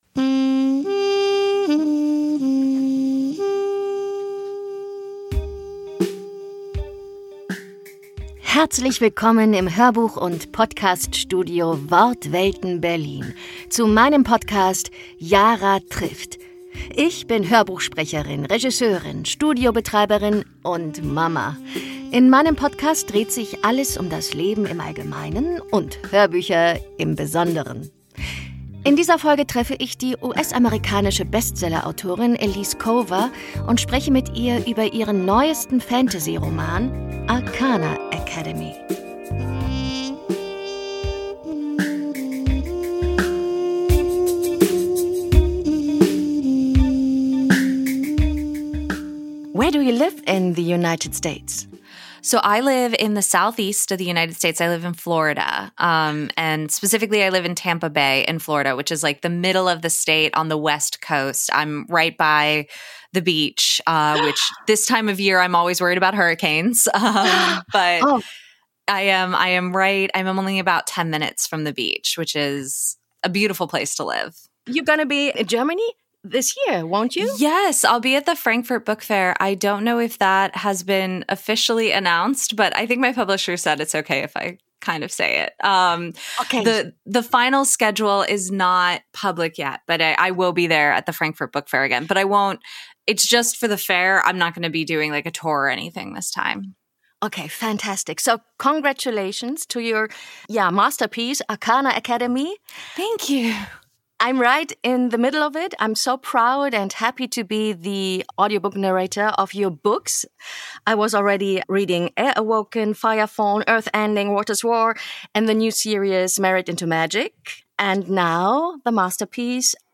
Im Gespräch erzählt Elise Kova, wie die Idee zur Arcana Academy entstand, welche magischen Geheimnisse die Hörer*innen erwarten und warum Romantasy für sie das perfekte Genre ist, um Emotionen, Abenteuer und Magie zu vereinen. Außerdem gibt sie Einblicke in ihren Schreiballtag, die Zusammenarbeit mit ihrem Team und den besonderen Moment, wenn aus einer Idee eine ganze Buchwelt wird.